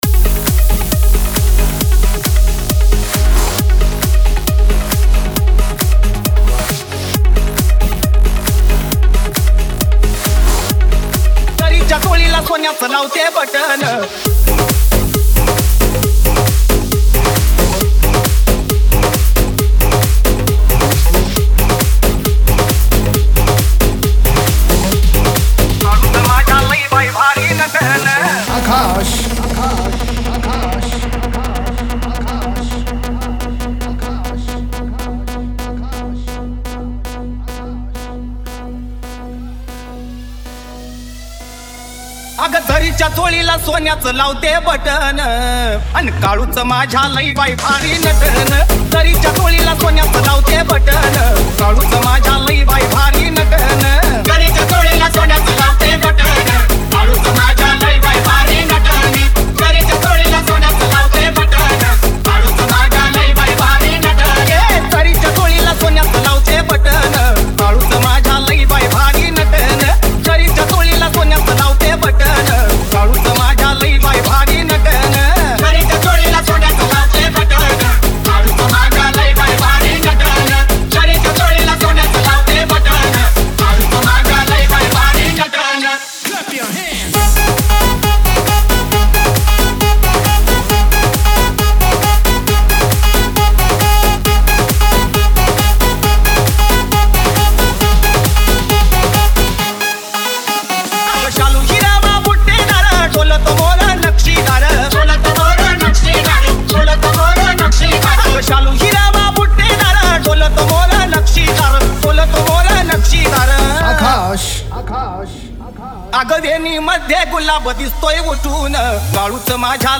- bhakti dj song